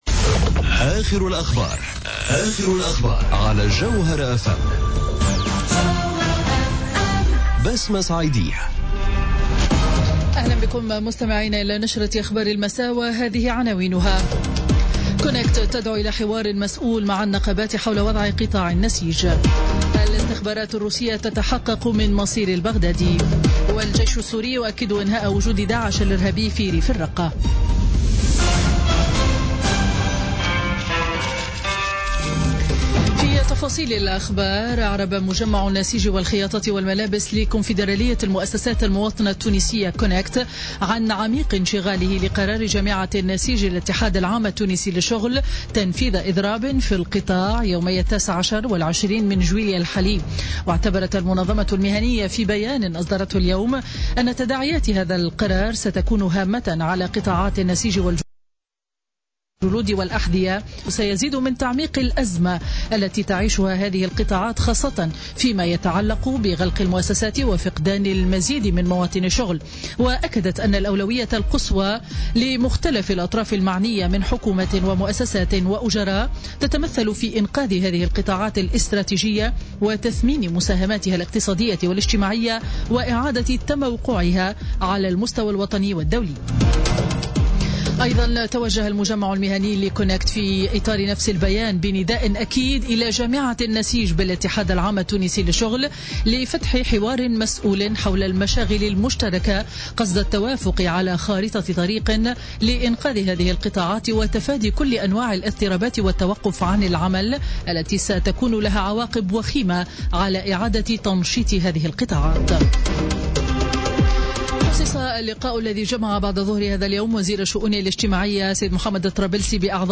نشرة أخبار السابعة مساء ليوم الاثنين 17 جويلية 2017